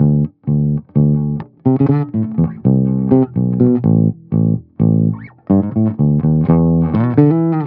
04 Bass Loop A.wav